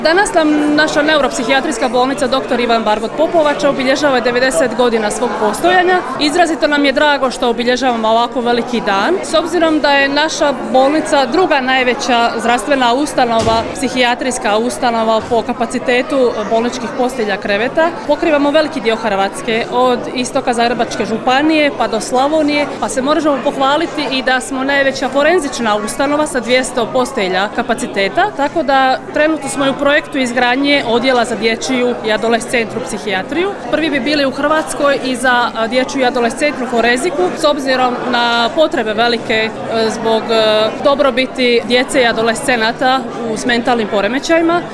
U povodu obilježavanja 90 godina Neuropsihijatrijske bolnice „dr. Ivan Barbot” Popovača u petak, 22. studenog 2024. godine, u Domu kulture u Popovači održana je prigodna svečanost.